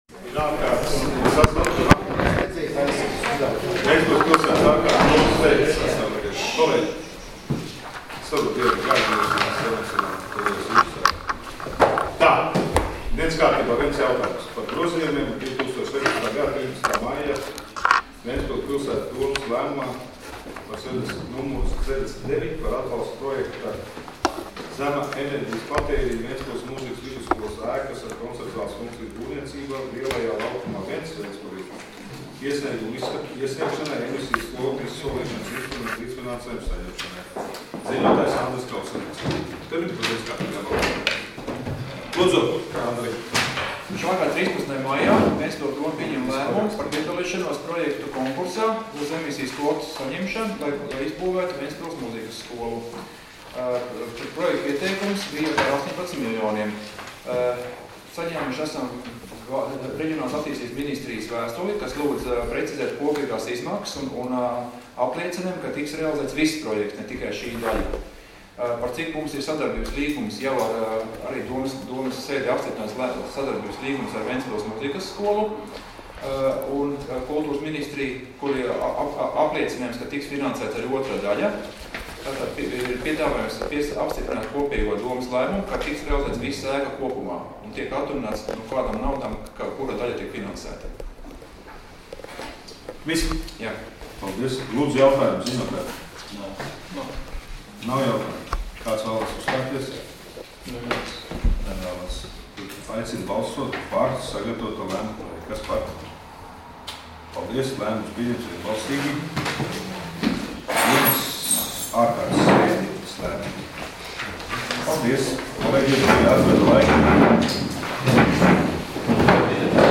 Domes sēdes 06.07.2016. audioieraksts